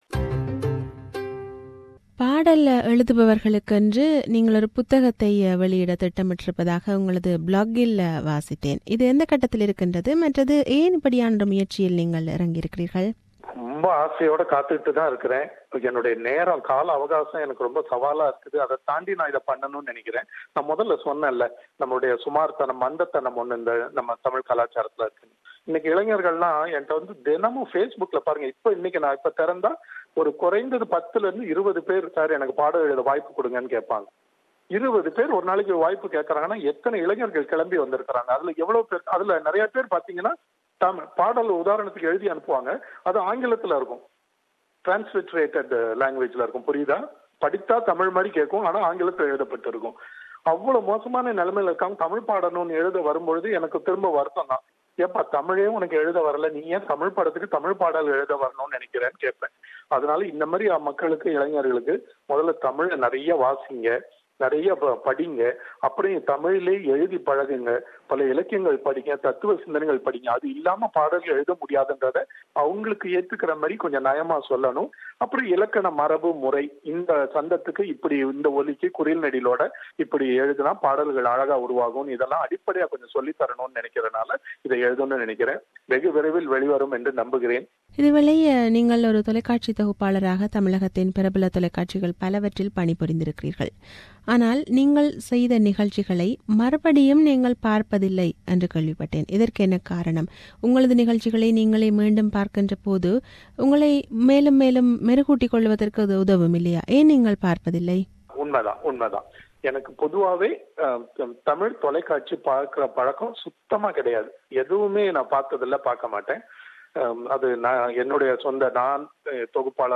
This is an interview with James Vasanthan.